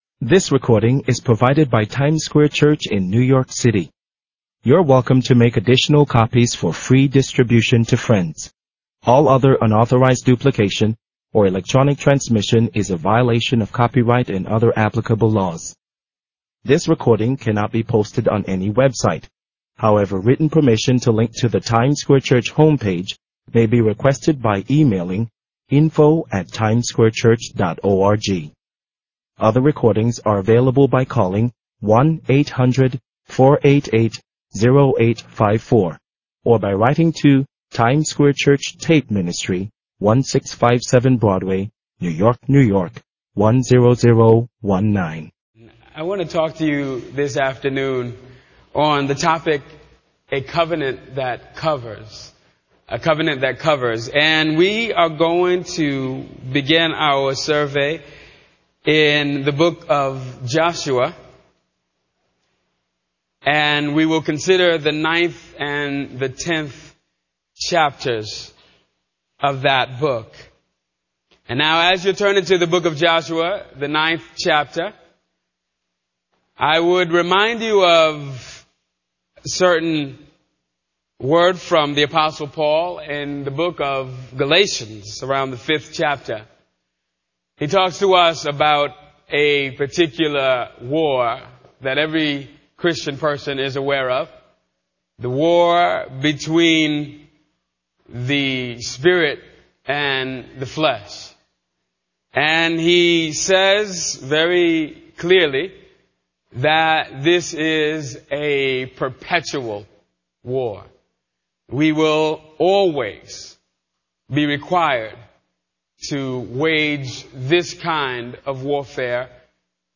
In this sermon, the preacher discusses the concept of making bad decisions and how they can become a part of our lives. He uses the analogy of being hewers of wood and drawers of water, which represents serving others. The preacher emphasizes that these bad decisions can be brought under subjection …